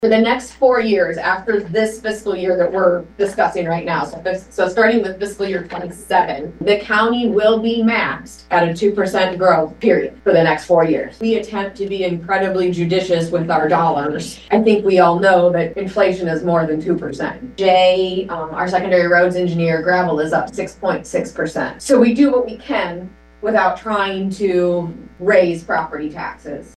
Franklin Supervisors hold a public hearing on the proposed property tax levy
Franklin County Auditor Katy Flint described what could happen if a property tax reform bill would be passed by the legislature.